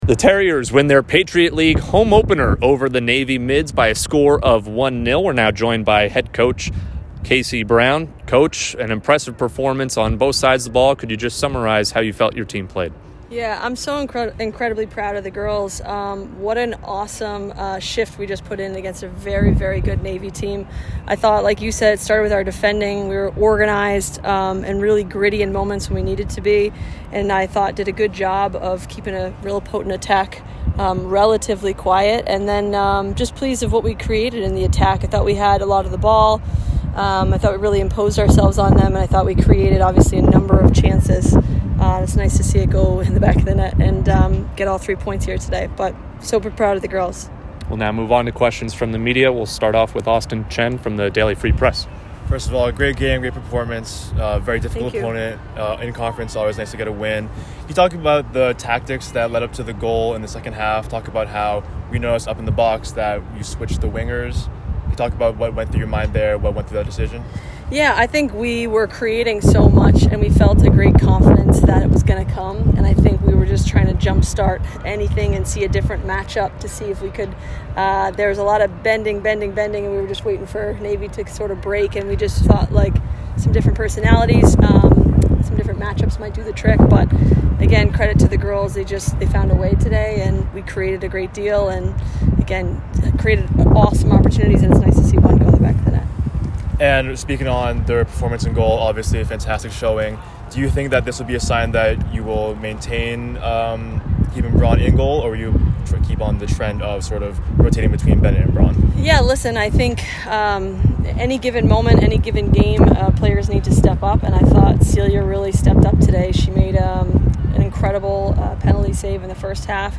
Women's Soccer / Navy Postgame Interview (9-24-22)